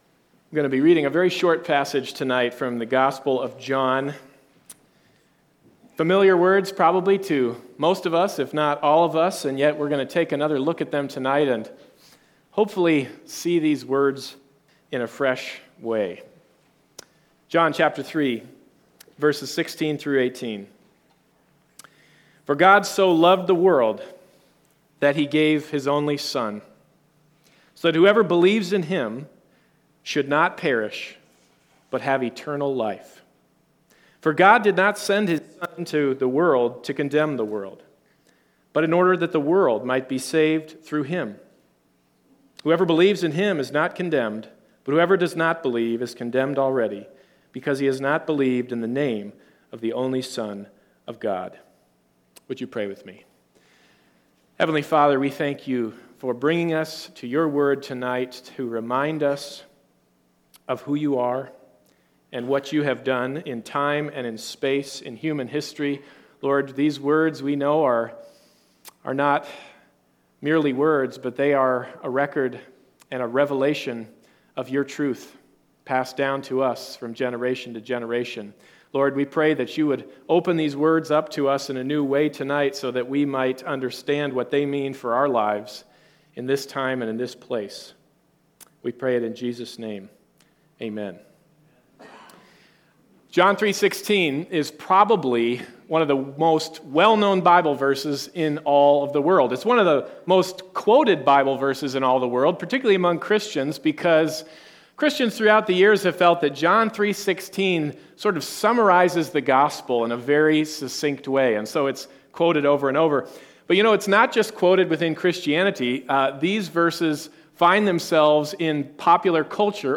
Bible Text: John 3:16 | Preacher